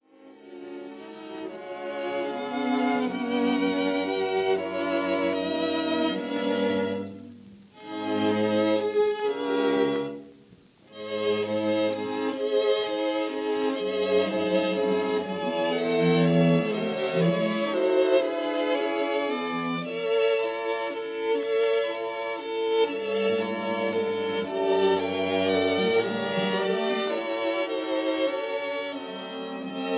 Cello
recorded this quintet in 1936 at Londons Abbey Road Studio.